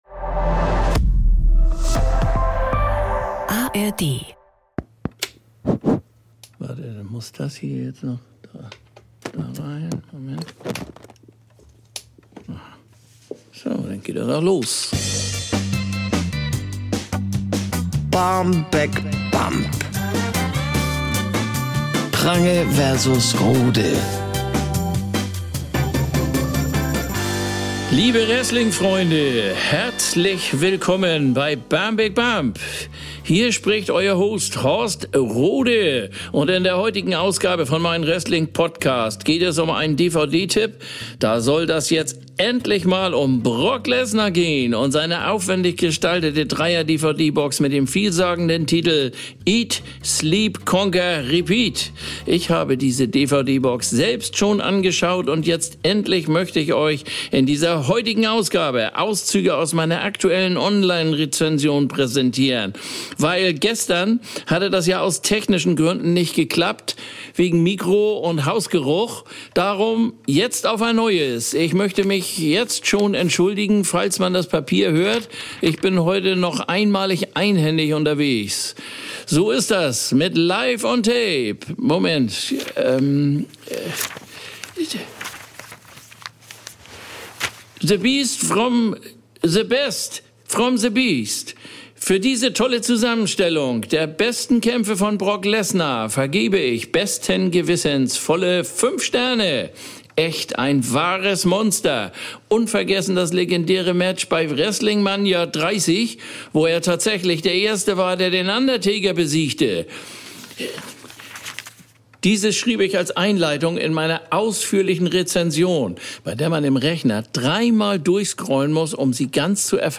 Ralf Prange: Bjarne Mädel Horst Rohde: Olli Dittrich Sprecherin: Doris Kunstmann